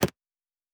pgs/Assets/Audio/Sci-Fi Sounds/Interface/Click 1.wav
Click 1.wav